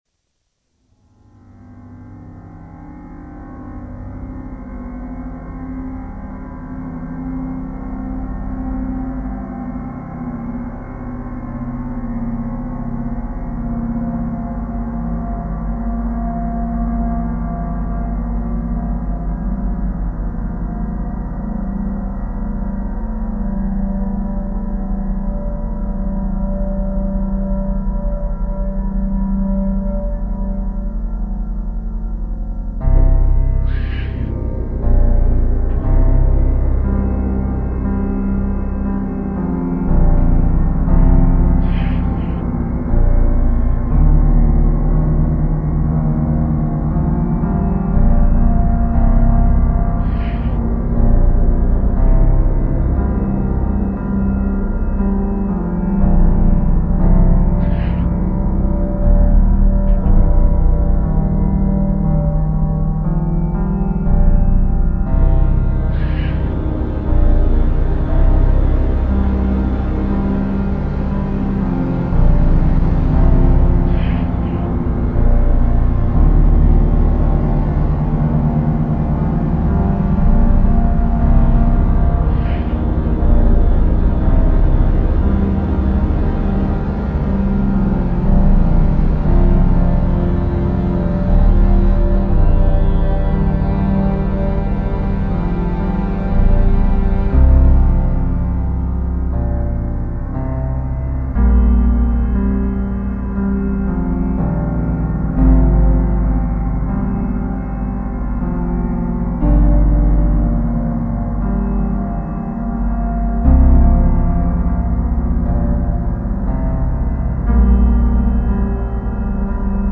Piano...introspective.